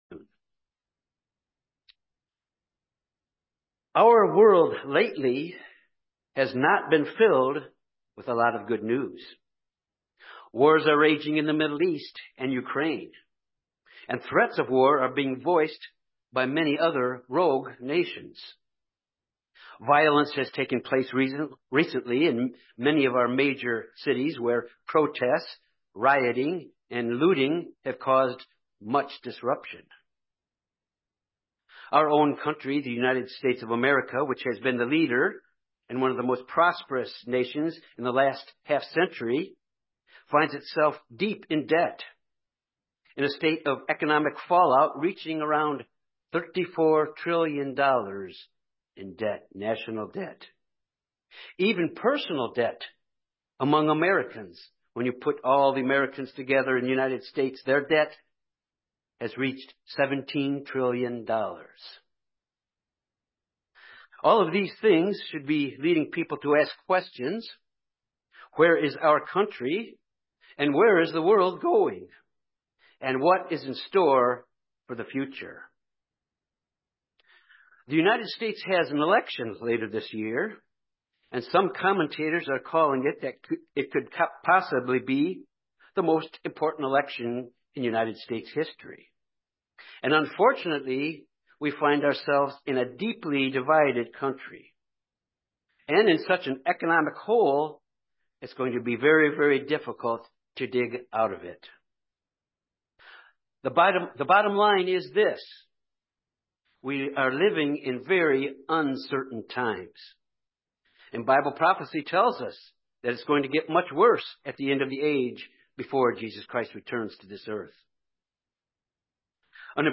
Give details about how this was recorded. Given in Jonesboro, AR Little Rock, AR Memphis, TN